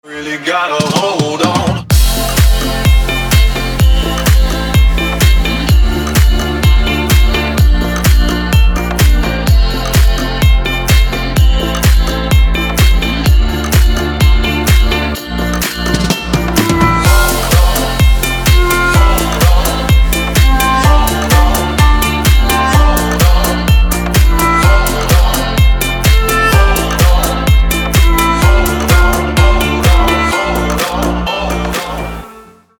dance
EDM